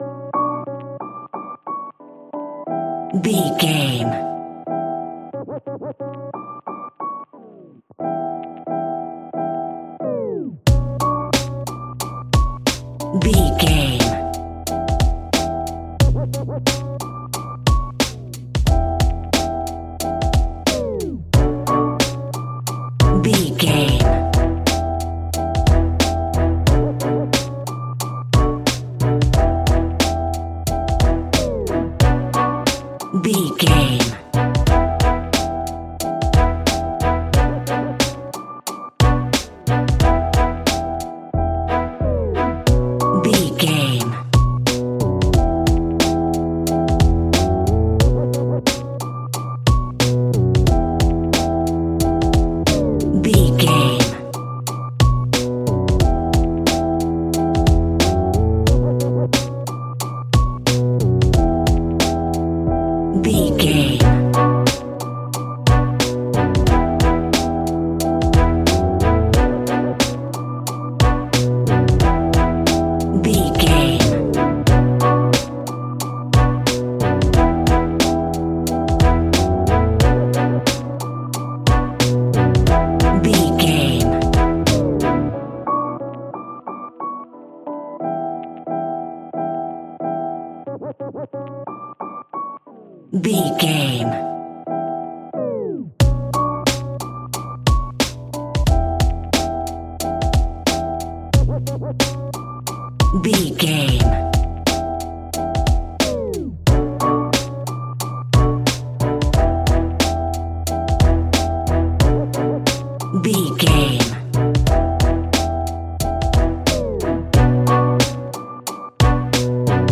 Aeolian/Minor
instrumentals
chilled
laid back
groove
hip hop drums
hip hop synths
piano
hip hop pads